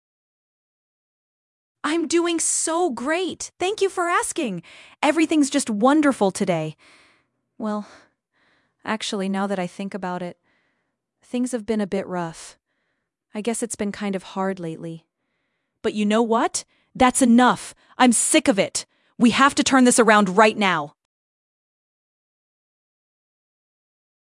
GPT-realtime是一个专用于语音AIAgent的多模态模型，能够生成更加自然流畅的语音，完美模仿人类丰富多样的语调、情感以及语速，支持图像理解并将其与语音或文本对话相结合使用，非常适用于客服、教育、金融、医疗等领域打造语音智能体。